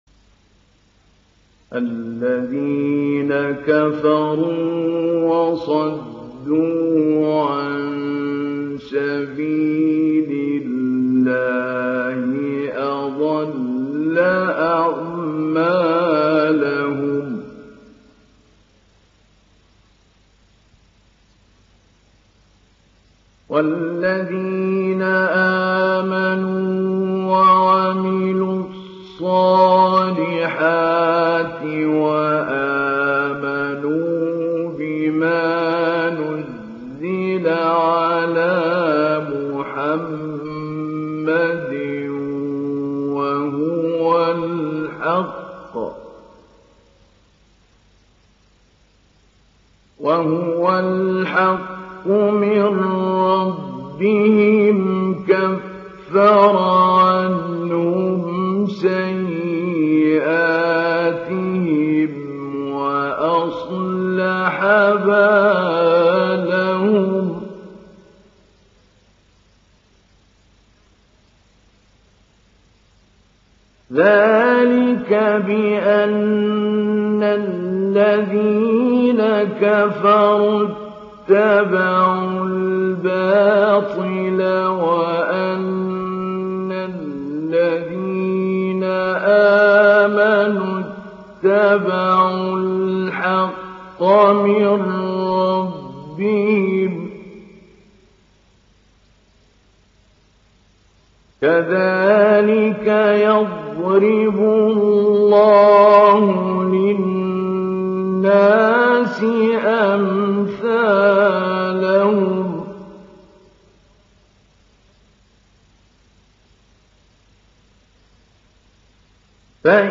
تحميل سورة محمد mp3 محمود علي البنا مجود (رواية حفص)
تحميل سورة محمد محمود علي البنا مجود